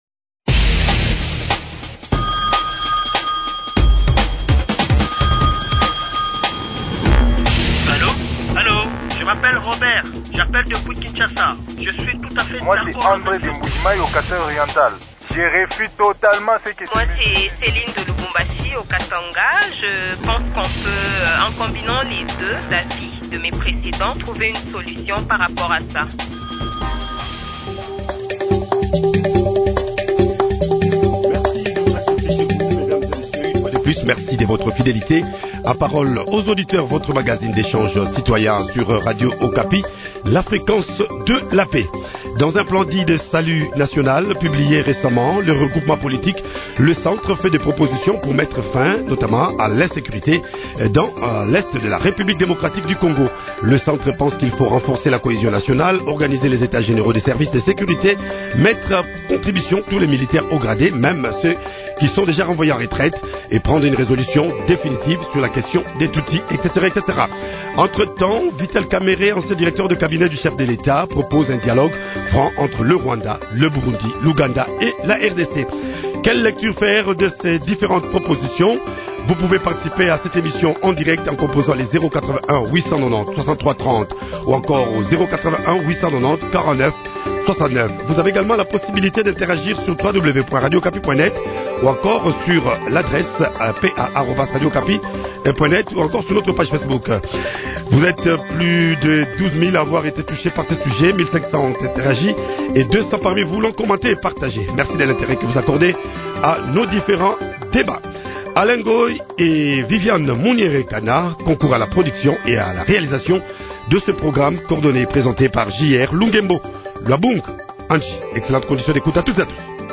brut_paroles_auditeurs-_insecurite_est-site.mp3